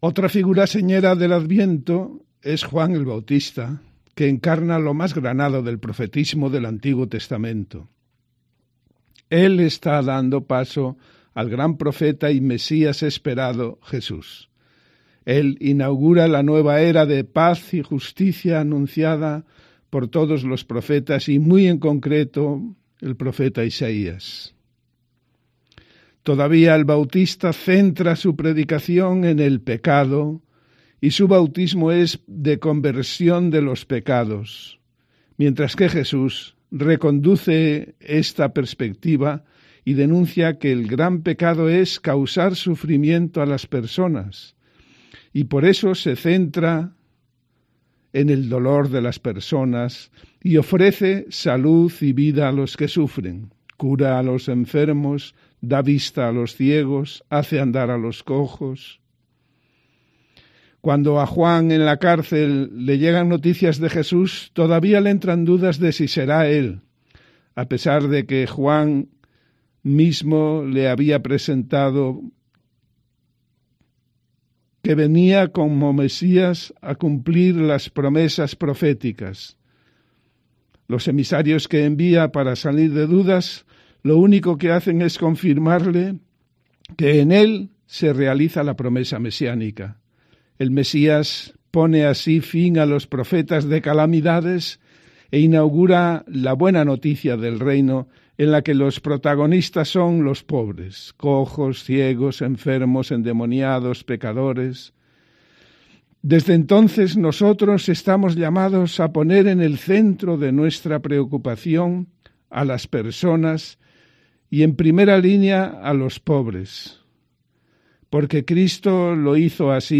Comentario del Evangelio de este domingo 15 de diciembre